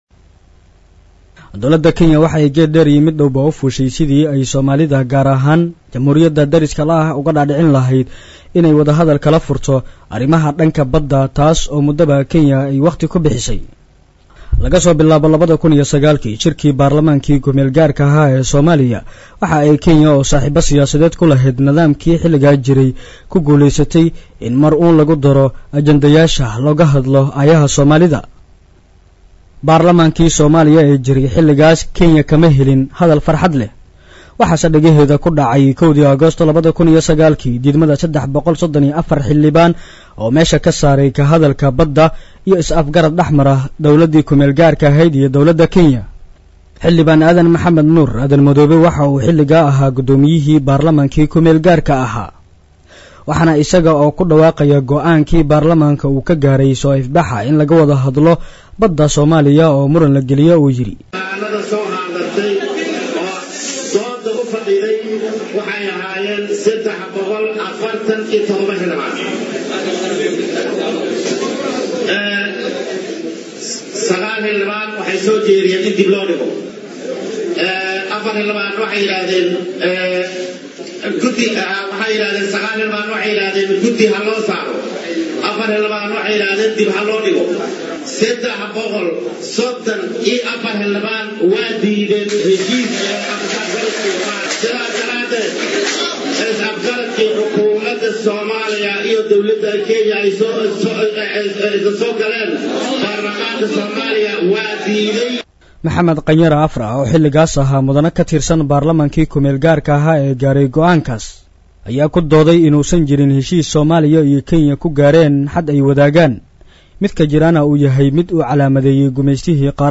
DHAGEYSO_-Warbixin-ku-saabsan-Damaca-Kenya-badda.mp3